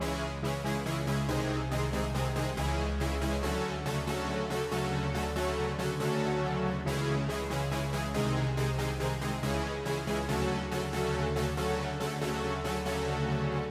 铜管乐器低八度 Bpm 140
Tag: 140 bpm Trap Loops Brass Loops 2.31 MB wav Key : Unknown